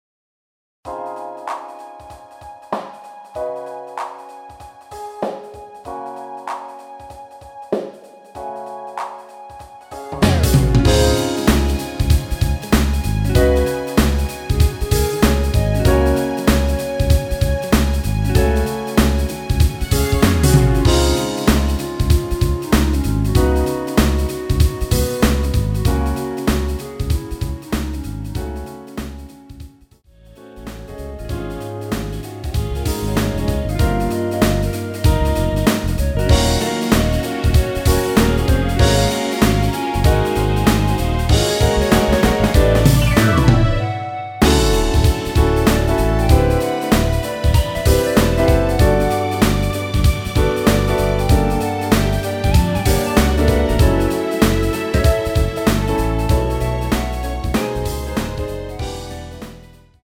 원키에서(-7)내린 남성분이 부르실수 있는키의 멜로디 포함된 MR입니다.(미리듣기 참조)
Db
앞부분30초, 뒷부분30초씩 편집해서 올려 드리고 있습니다.
중간에 음이 끈어지고 다시 나오는 이유는